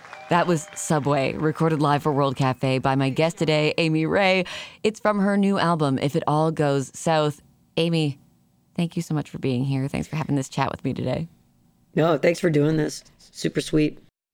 (audio capture from webcast)
07. interview (0:14)